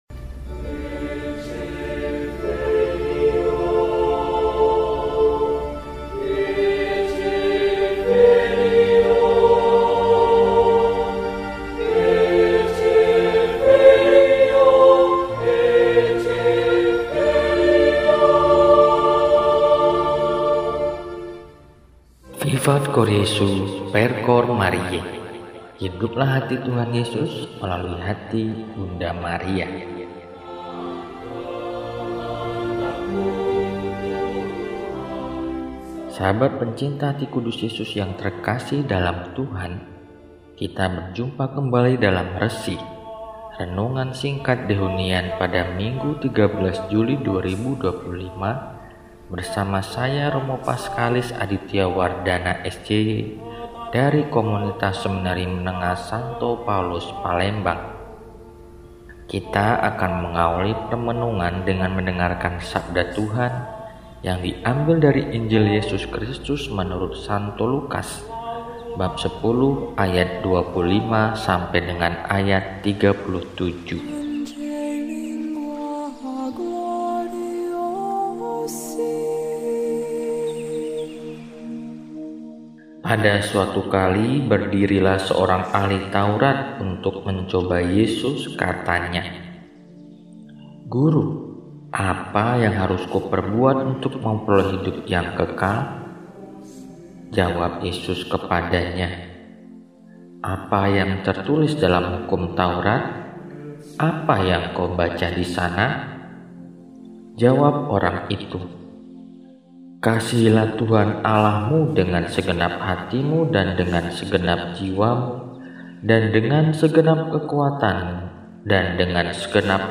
Minggu, 13 Juli 2025 – Hari Minggu Biasa XV – RESI (Renungan Singkat) DEHONIAN